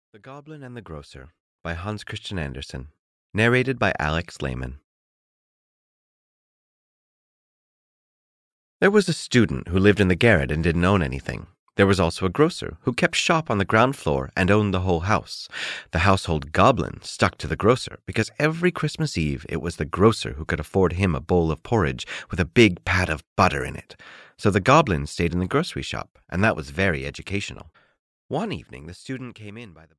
The Goblin and the Grocer (EN) audiokniha
Ukázka z knihy